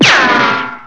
Ricochet.wav